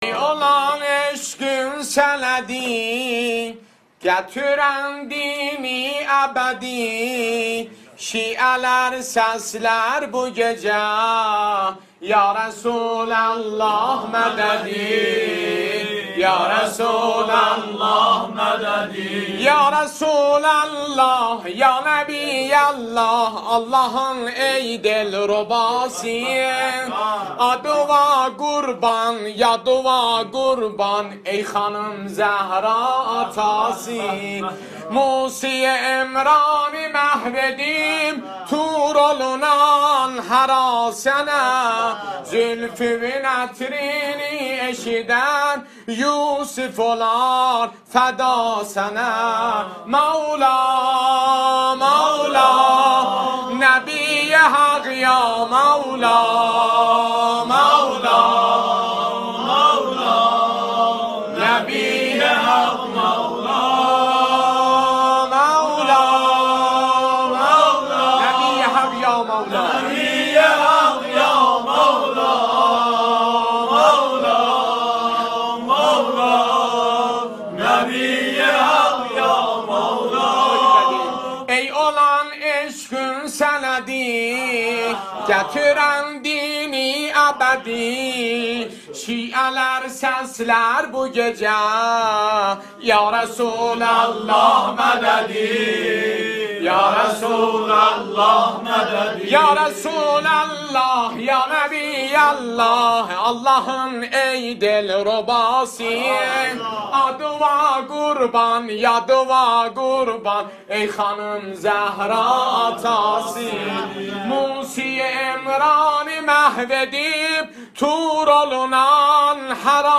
مولودی آذری مولودی ترکی